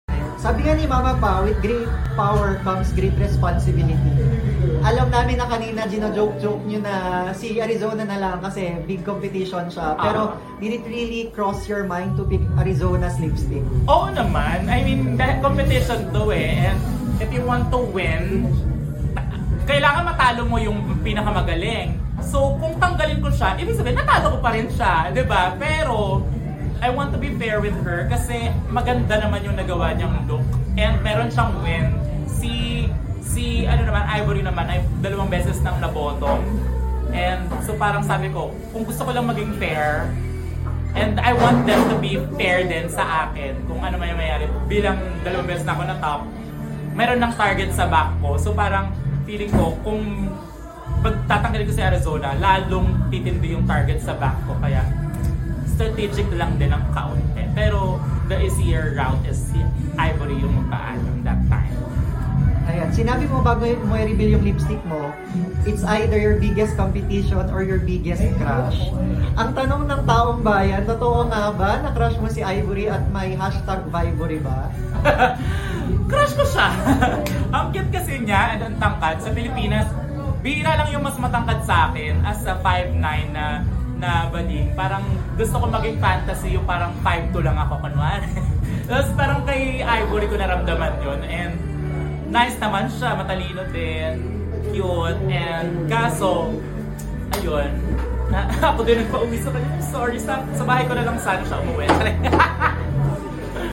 at the sidelines of the "D'Intervention" viewing party in Quezon City